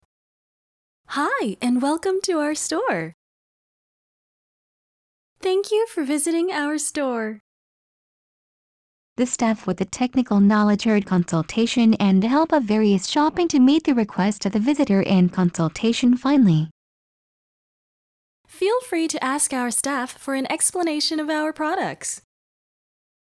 この度、サイバーサイネージに搭載される「ＶＮＳＳ」では、文字を入力するだけで音声データに変換され、音声素材としてデジタルサイネージに利用可能となります。
あわせてサイバーサイネージでは、店舗で使う「いらっしゃいませ」「海外発送も承ります」などの定番フレーズの音声データを、ネイティブスピーカーのナレーションにて、日本語・英語・中国語・韓国語の４ヵ国語で用意します。
《「ＶＮＳＳ」と「リアルナレーション音声データ」を組み合わせた音声データのサンプル》
□英語